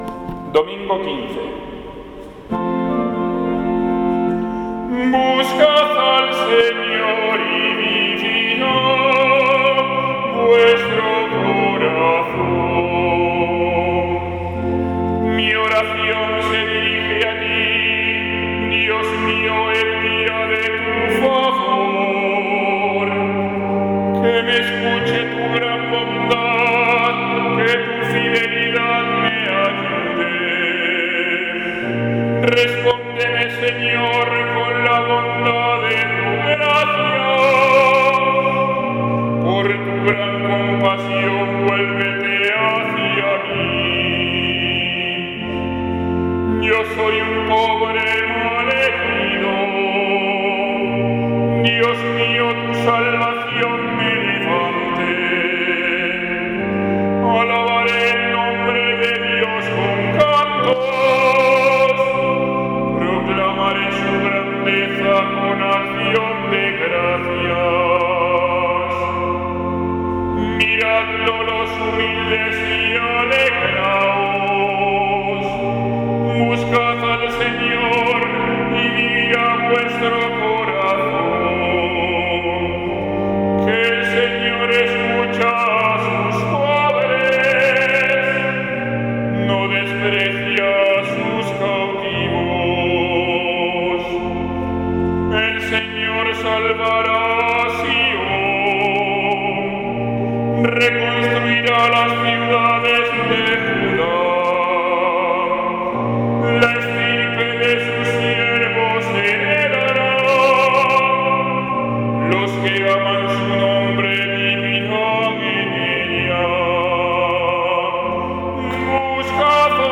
Salmo Responsorial 68/ 14 y 17; 30-34